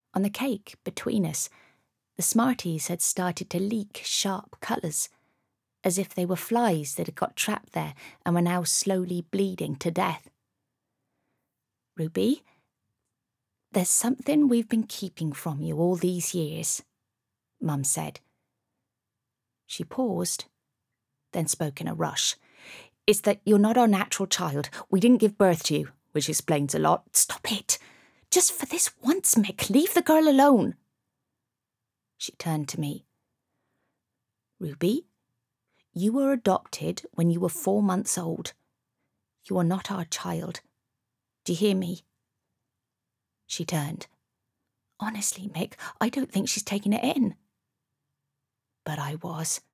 I have a naturally husky, sexy mid tone.
Native RP, Native Cornish and Fantasy